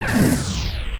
Weapon_LightningGun.ogg